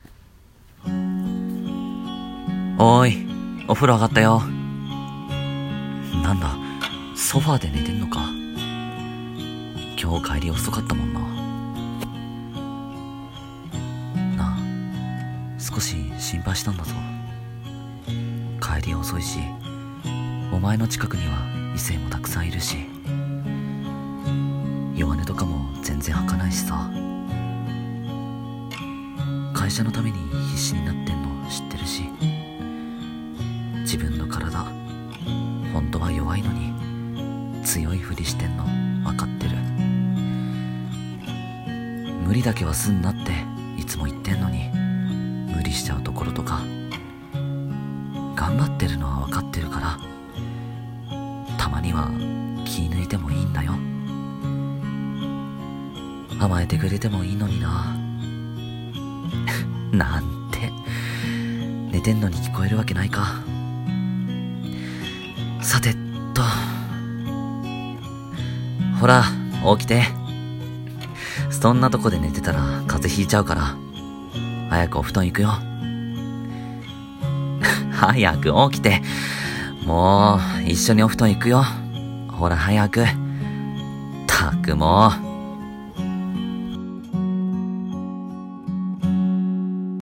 声劇台本 ｢眠れるキミに伝えたい｣